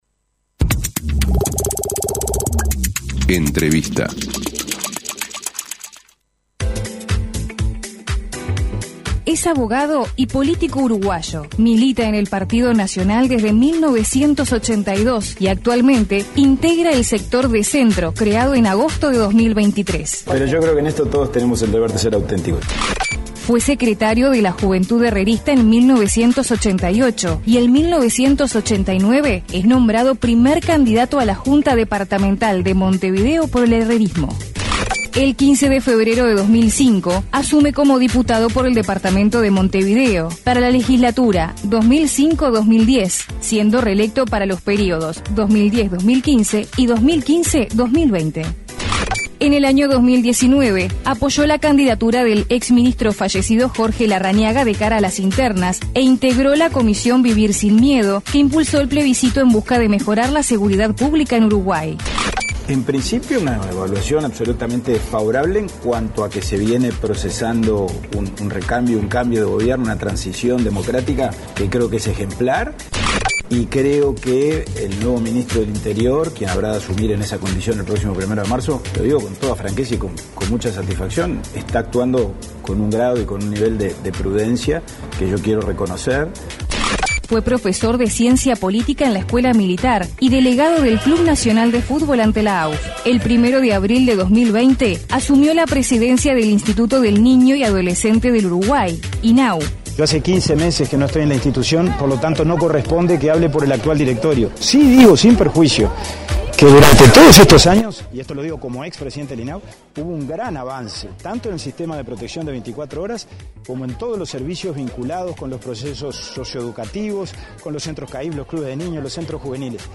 Entrevista al subsecretario del Interior Pablo Abdala